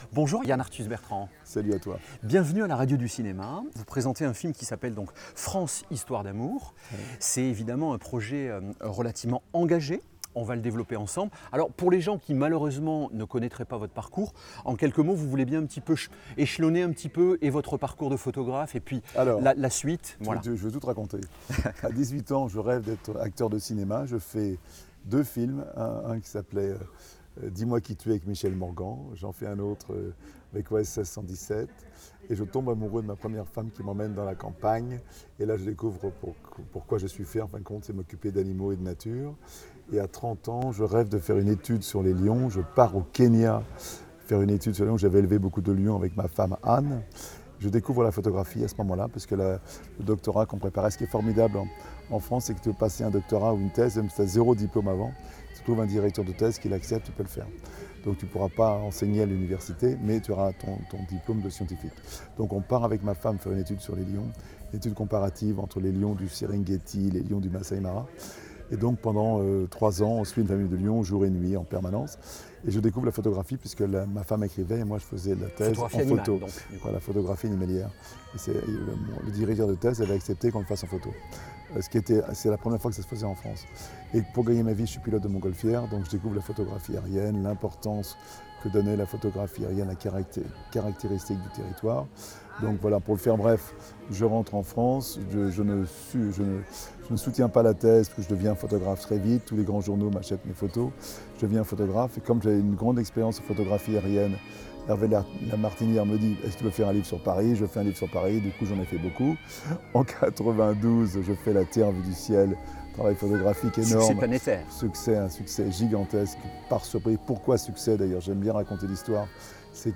%%Les podcasts, interviews, critiques, chroniques de la RADIO DU CINEMA%% La Radio du Cinéma Yann Arthus-Bertrand :